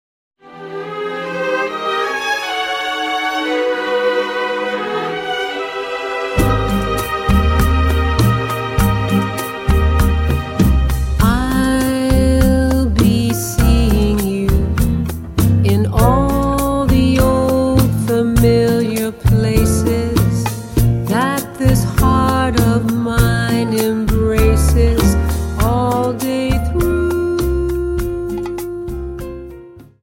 Dance: Rumba 25